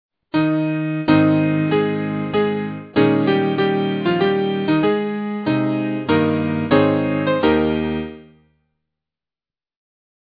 Voici, pour célébrer le 250ème anniversaire de Ludwig van Beethoven, une courte pièce pour piano “dans le style” de Beethoven.